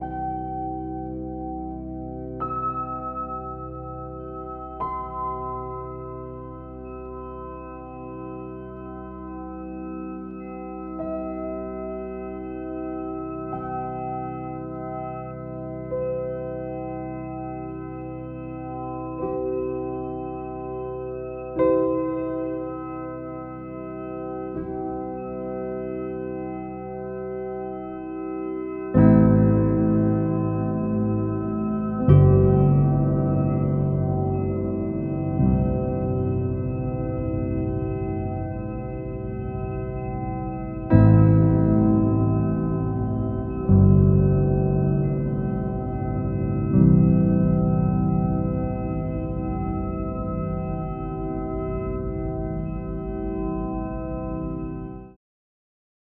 Ce morceau est exclusivement basé sur la fréquence de l’Eau.